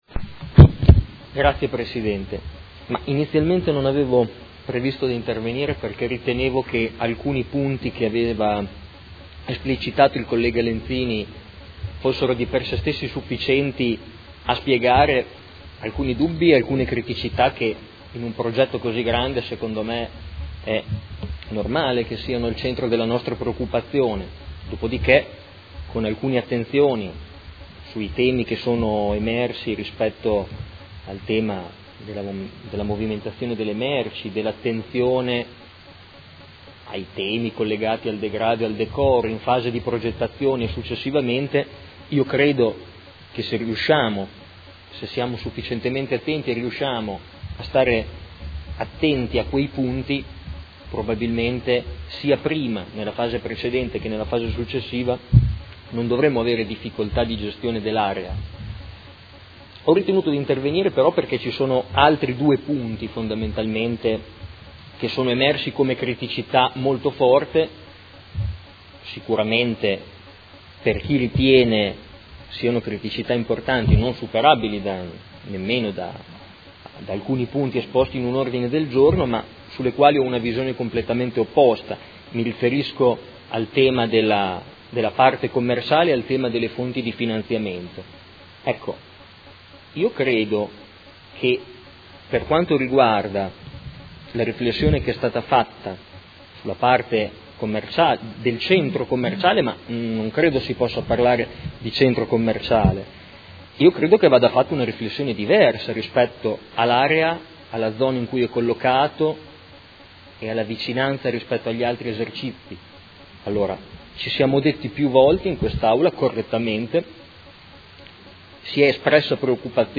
Seduta del 12/07/2018. Dibattito su proposta di deliberazione: Riqualificazione, recupero e rigenerazione urbana del comparto “ex sede AMCM” - Valutazione degli esiti della procedura competitiva con negoziazione in relazione ai contenuti del Documento di Indirizzo.